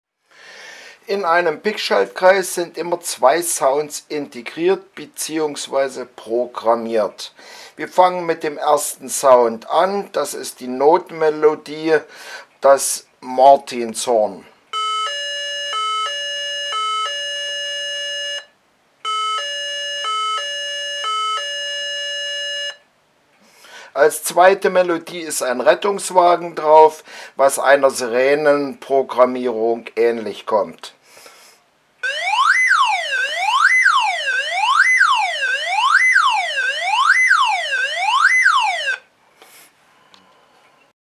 A-Martinshorn+Rettungswagen
A-Martinshorn+Rettungswagen.mp3